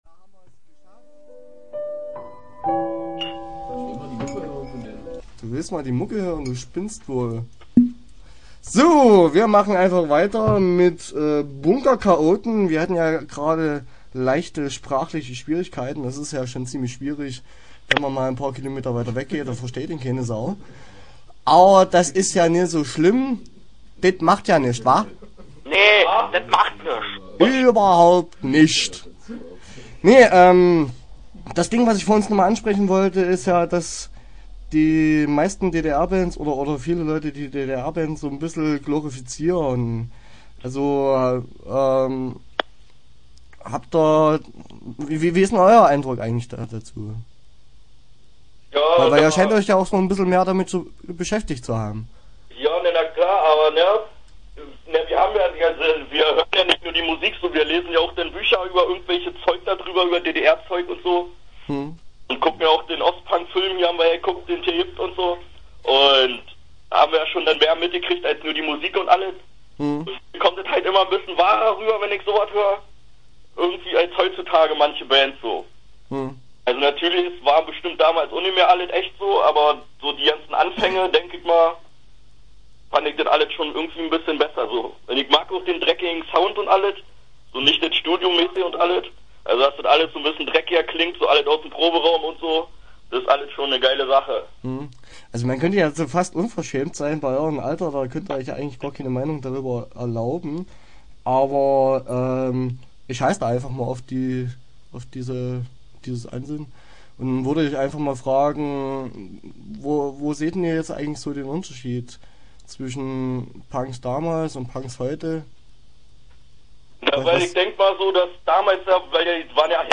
Interview Teil 1 (12:00)